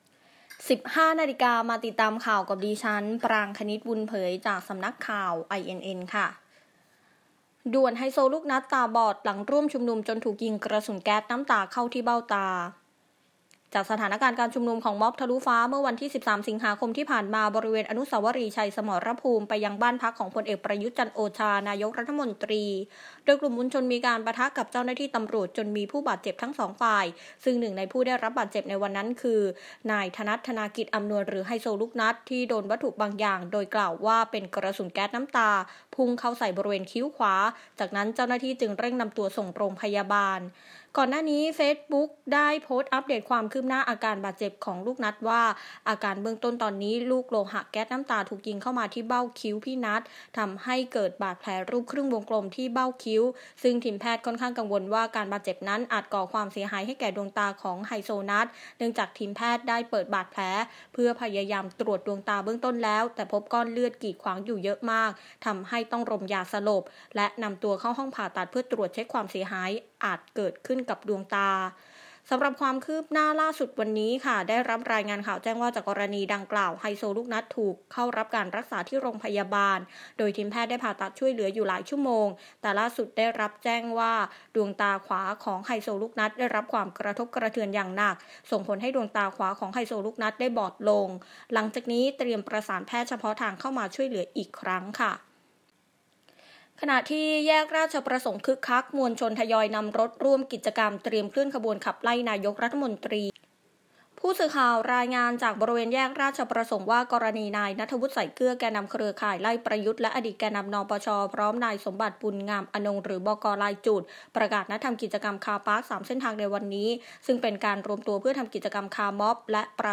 ข่าวต้นชั่วโมง 15.00 น.
ซึ่งที่บริเวณแยกราชประสงค์มีความคึกคัก ตั้งแต่เวลา 13.00 น. โดยทางกลุ่มผู้ชุมนุมได้ทยอยนำรถจักรยานยนต์และรถยนต์มาเรียงแถวจัดรูปแบบขบวนมีการบีบแตร และมีการเปิดเวทีคอนเสิร์ตขนาดย่อม ร้องเพลงกันอย่างสนุกสนาน